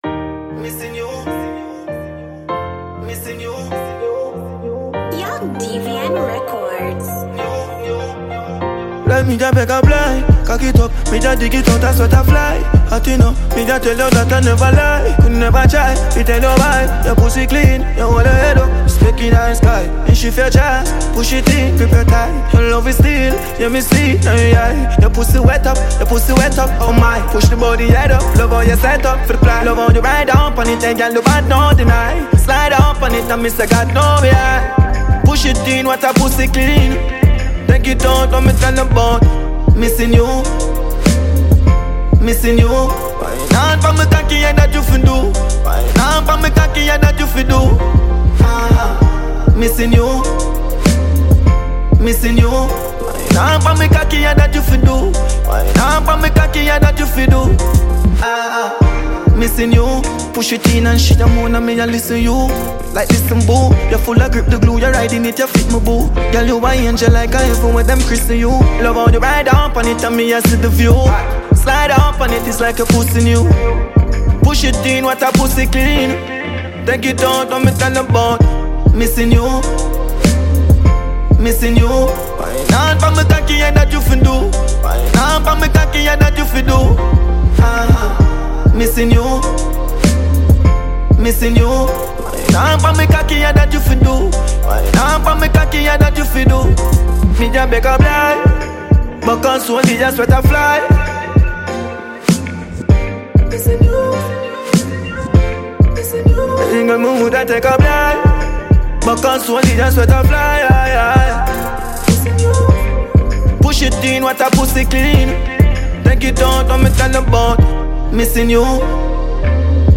dancehall music